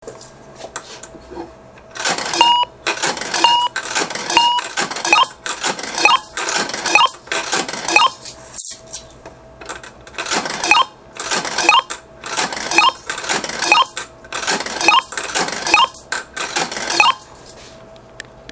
パチスロ実機